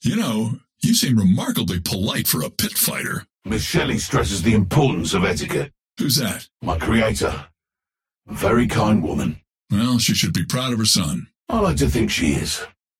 Bebop and Dynamo conversation 3